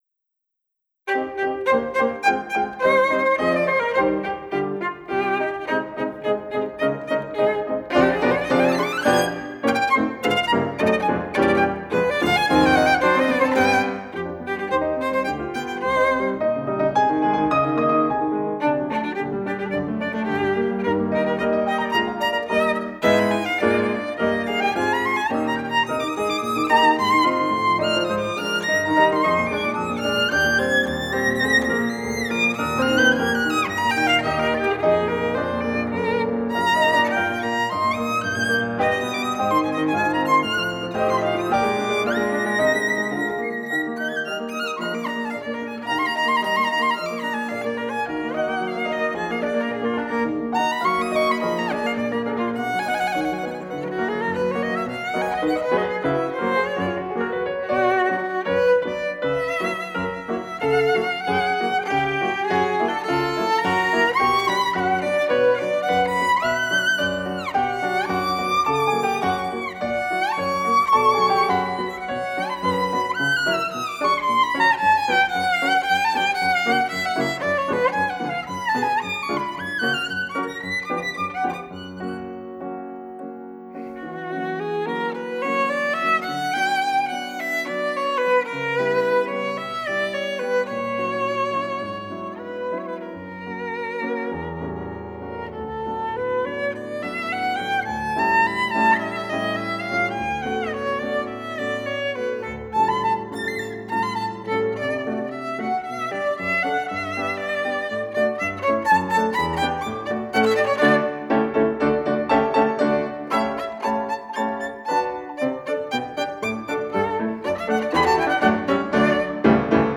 5 Sonata para violín y piano - IV Rondo.aiff (59.72 MB)